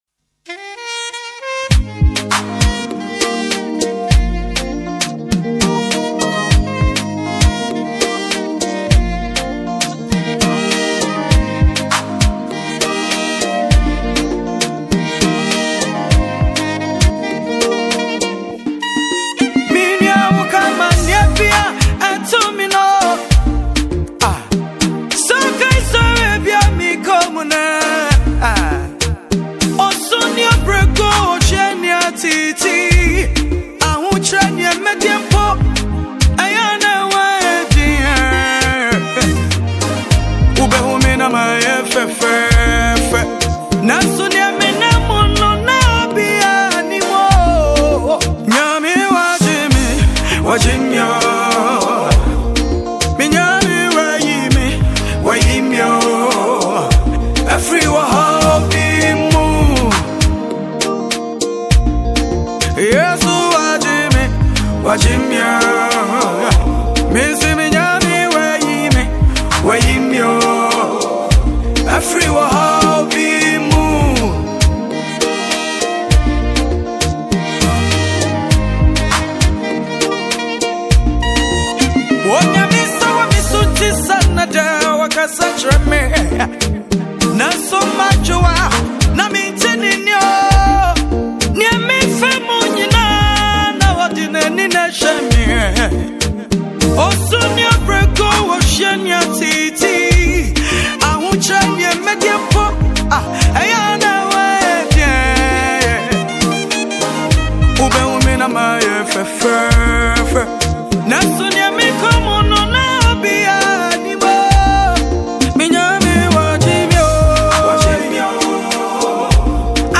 Gospel joint